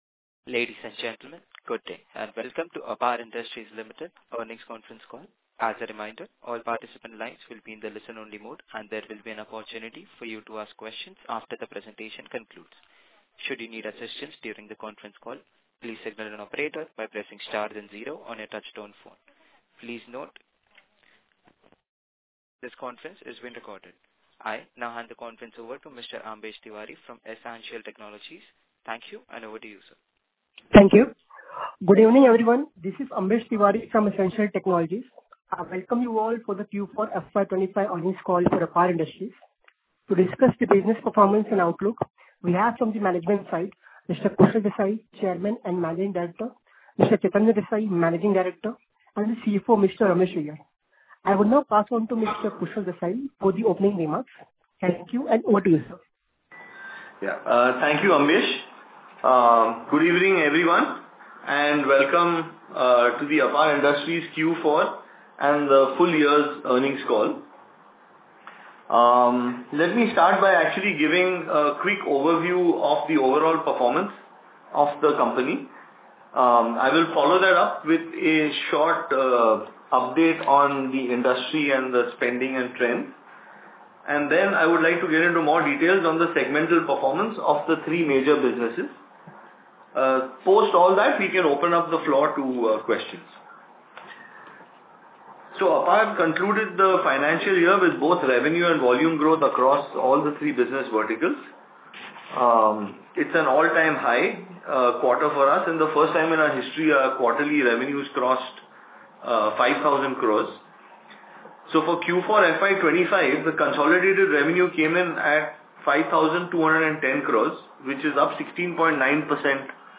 Concalls
Apar-Q4FY25-Earnings-Call-Audio.mp3